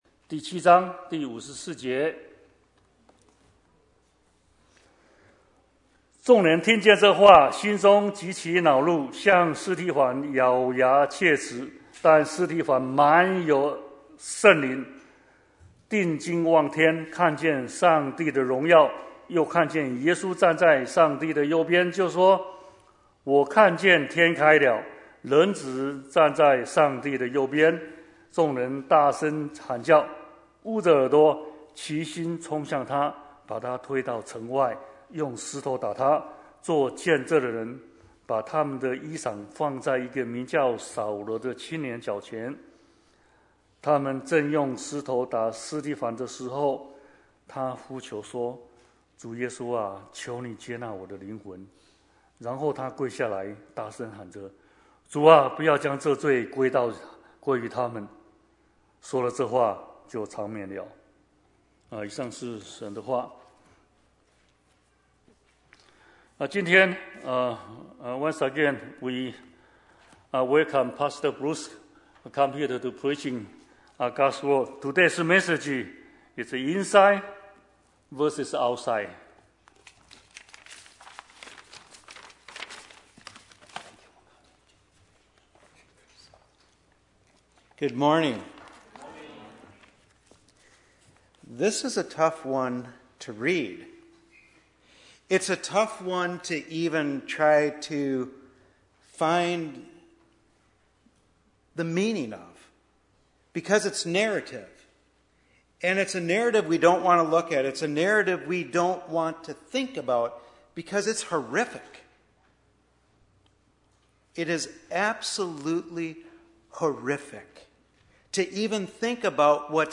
Bible Text: 使徒行傳 7:54~60 | Preacher